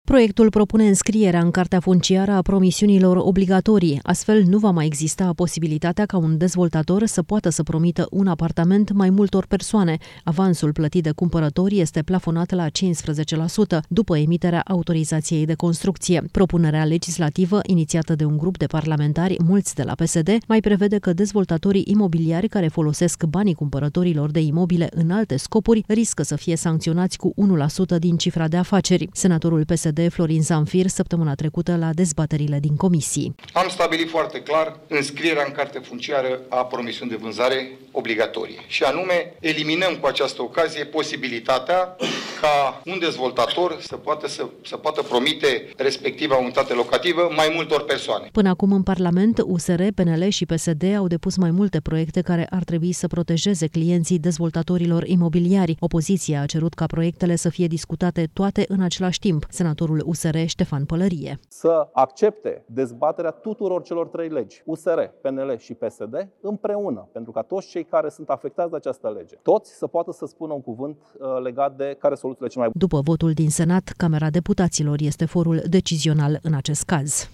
Senatorul PSD Florin Zamfir, săptămâna trecută, la dezbateriile din comisii: „Eliminăm cu această ocazie posibilitatea ca un dezvoltator să poată promite respecta unitate localitivă mai multor persoane”